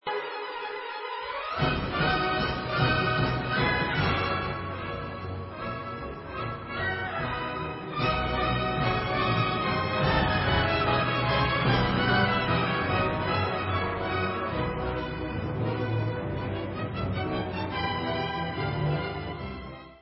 A dur (Allegro vivace) /Skočná